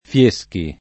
Fieschi
fL%Ski] cogn.